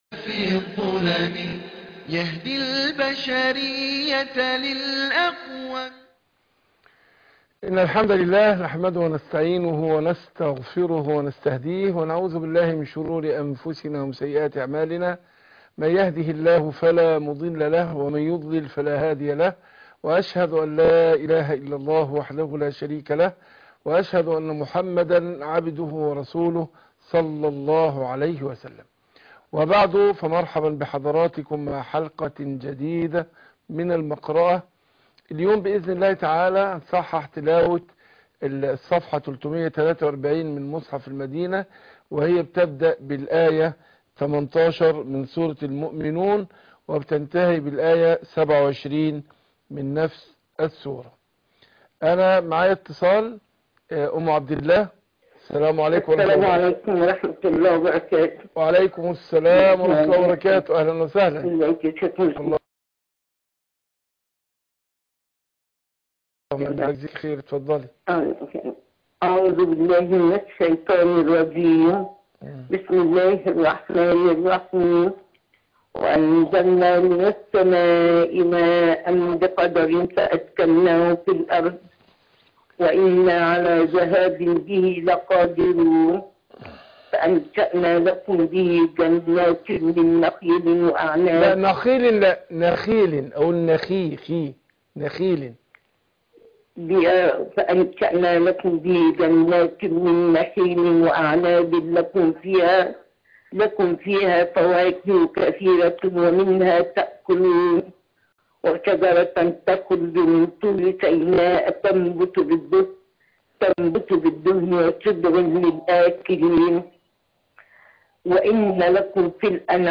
المقرأة - سورة المؤمنون ص 343